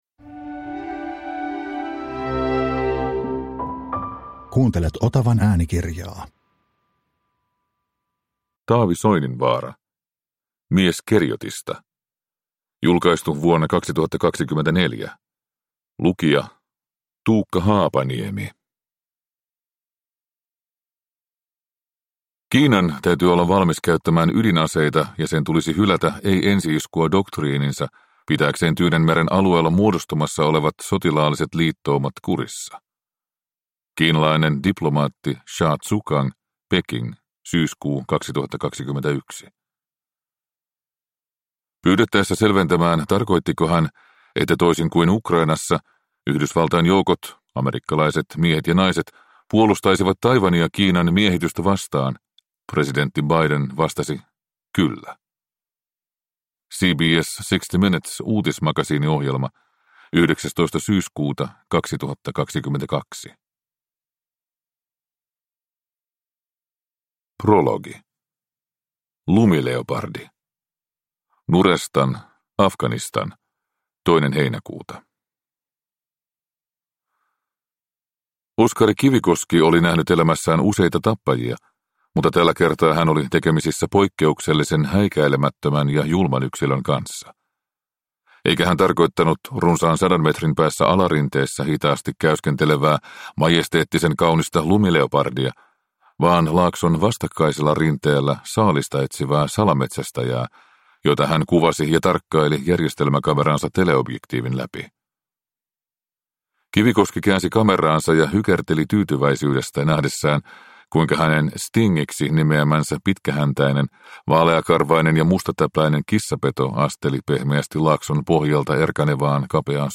Mies Keriotista (ljudbok) av Taavi Soininvaara